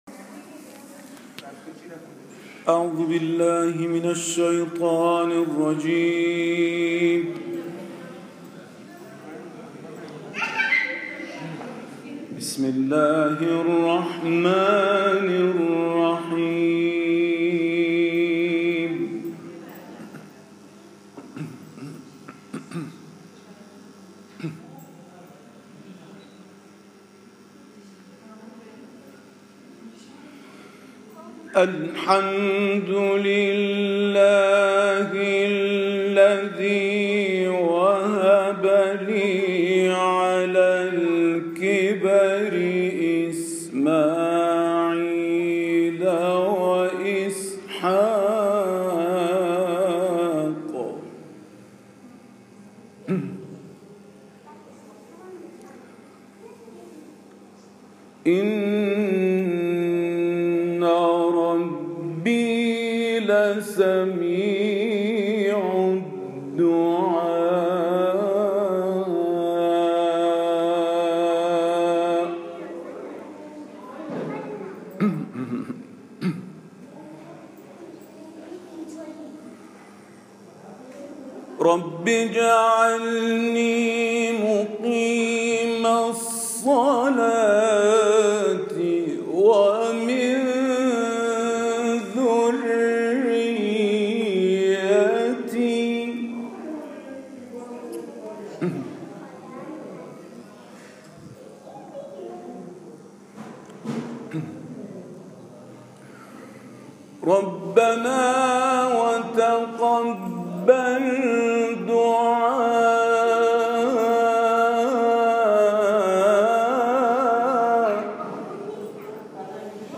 تلاوت آیات 39 تا 41 سوره مبارکه ابراهیم و سوره مبارکه حمد در شب شهادت مولا علي(ع) در مسجد جعفری شهر ميسور هند